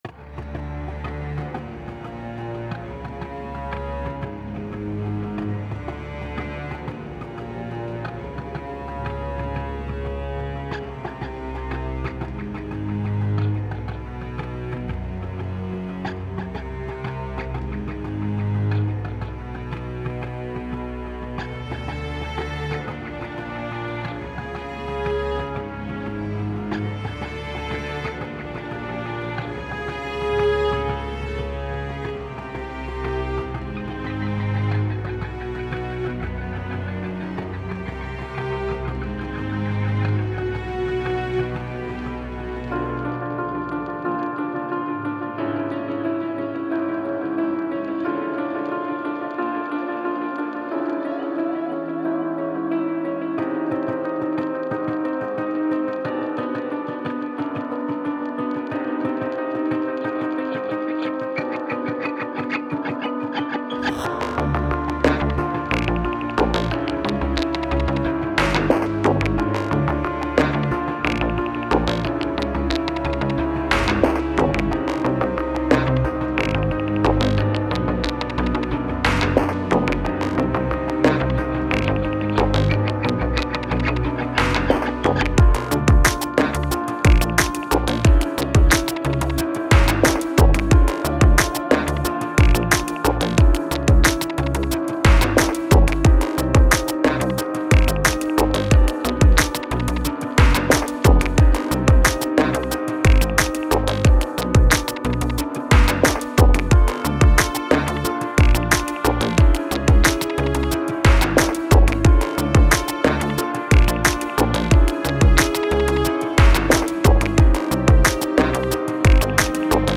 אני מצאתי את הדאבסטאפ כמעניין